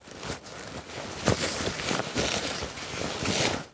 foley_sports_bag_movements_07.wav